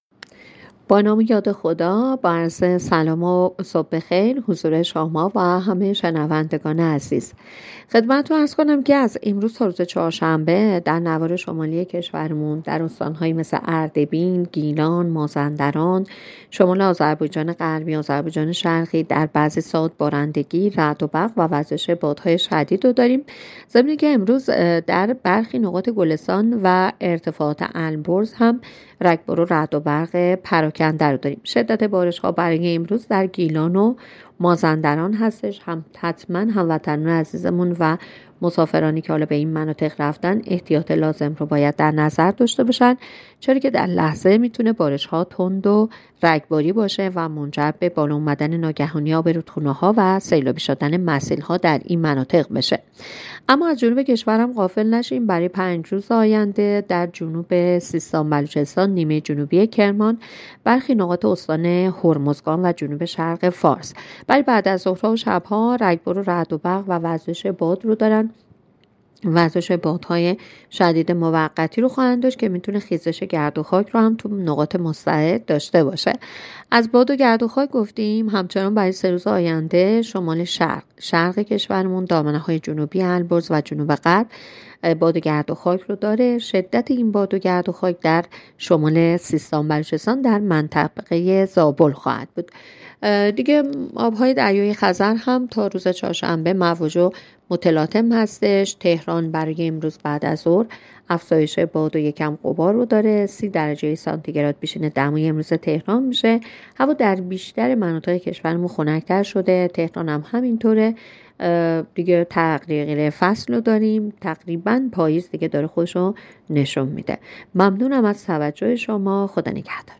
گزارش رادیو اینترنتی پایگاه‌ خبری از آخرین وضعیت آب‌وهوای ۲۴ شهریور؛